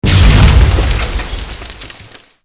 otherDefensiveSystemHit.wav